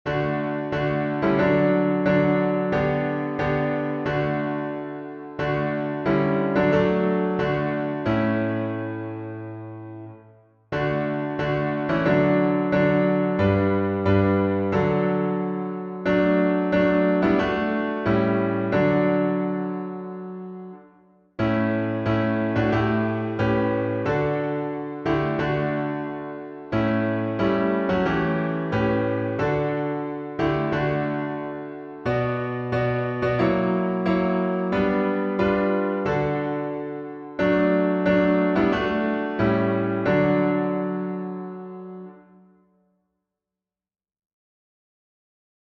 #2033: Lead Me to Calvary — D major | Mobile Hymns